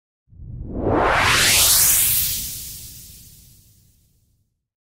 Categoria Efeitos Sonoros